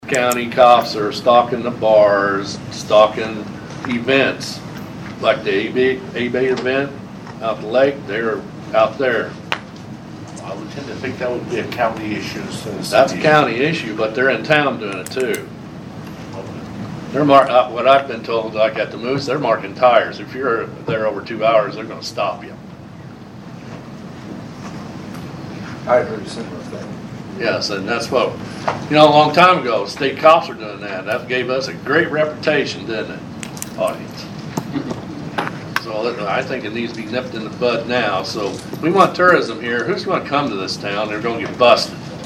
During Monday’s Vandalia City Council meeting, Alderman Ken Hubler spoke during the meeting about the complaints and said he wished something could be said to the Sheriff.